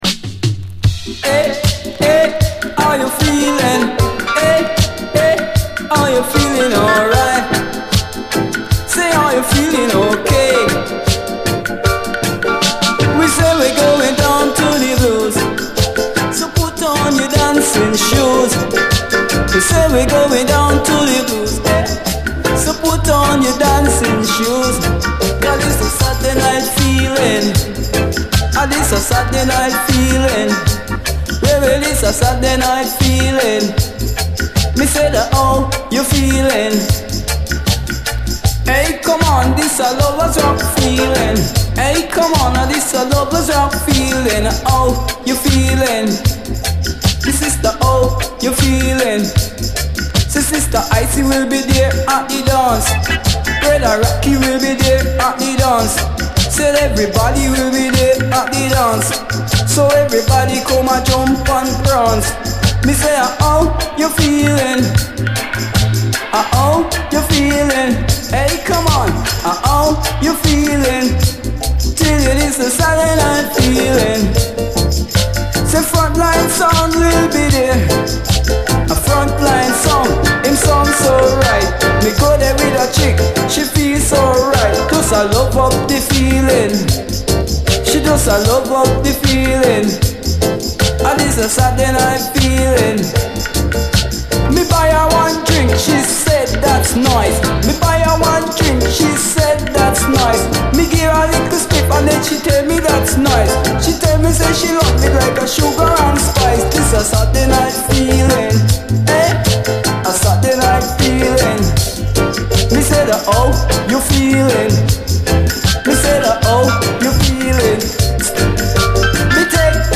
REGGAE
盤表面的なスレ結構見られますが実際は非常に綺麗に聴けます
後半はダブに接続！